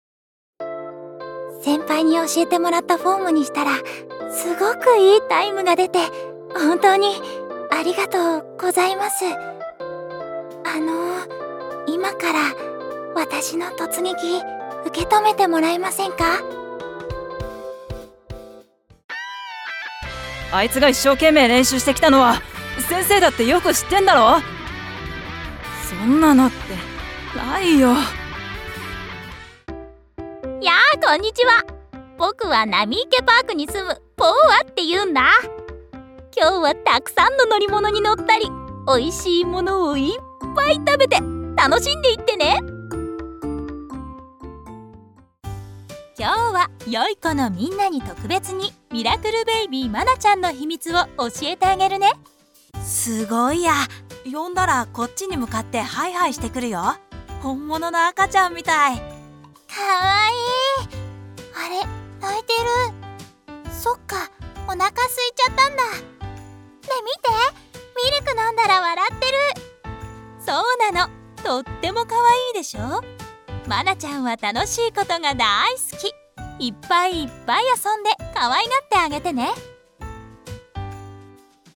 ボイスサンプル
• 【キャラクター】可愛い・少年・ゆるキャラ・お姉さん他演じ分け
少年少女〜おばあさんまで演じ分け可能。